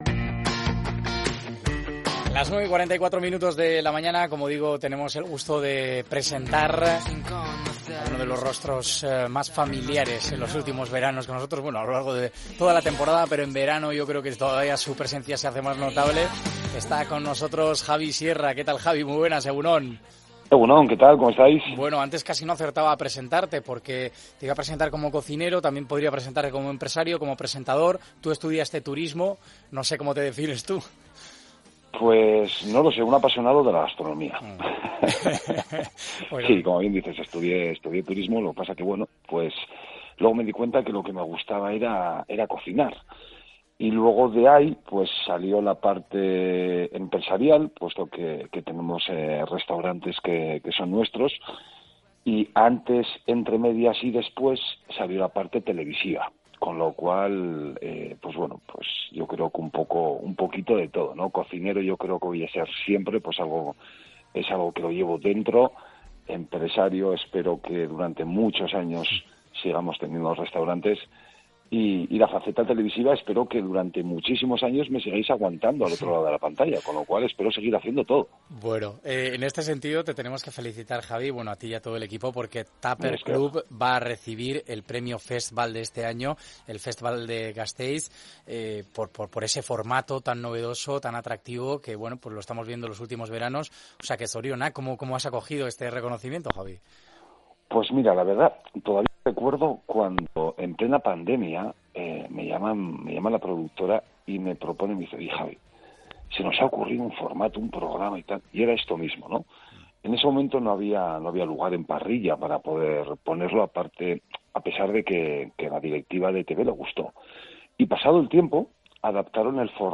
Hemos hablado con él de lo que se esconde detrás de las cámaras y de ese nuevo proyecto del que nos hace un adelanto. Una charla en la que nos desvela cosas muy interesantes y que seguro te hará disfrutar.